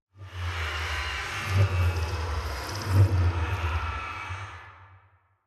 Minecraft Version Minecraft Version 1.21.5 Latest Release | Latest Snapshot 1.21.5 / assets / minecraft / sounds / block / respawn_anchor / ambient2.ogg Compare With Compare With Latest Release | Latest Snapshot
ambient2.ogg